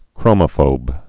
(krōmə-fōb) also chro·mo·pho·bic (-fōbĭk)